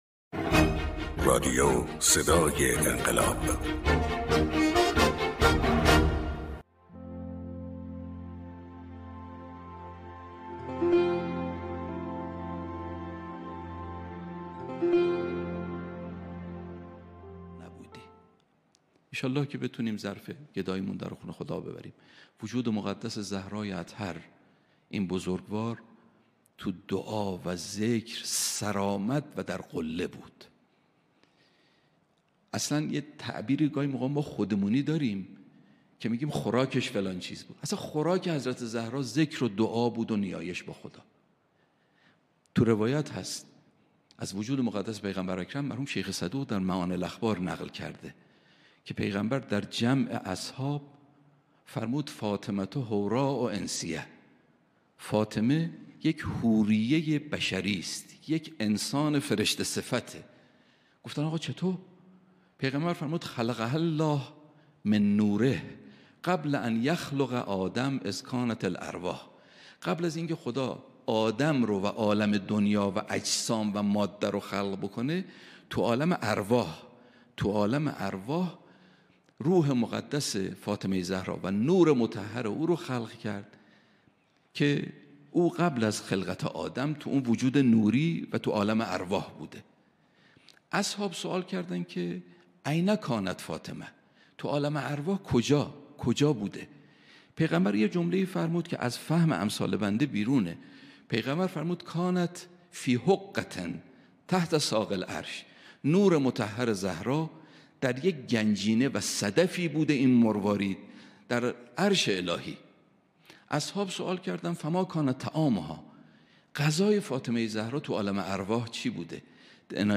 سخنران